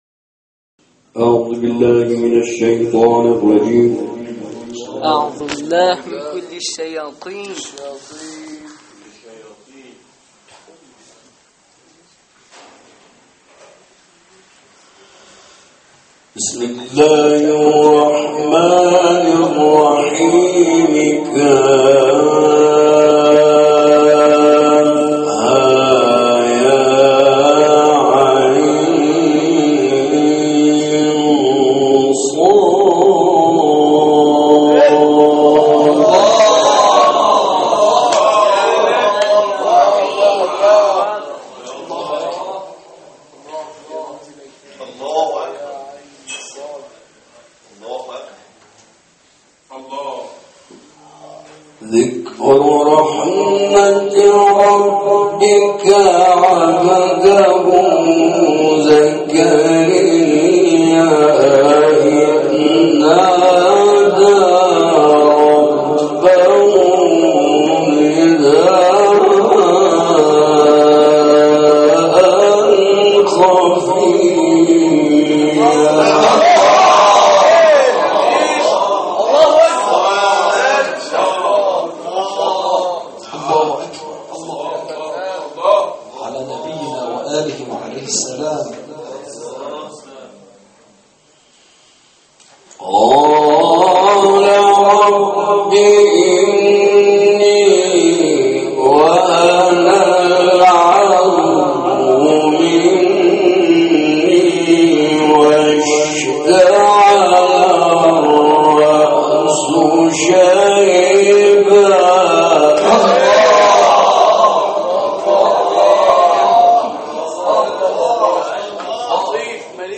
تلاوتی ماندگار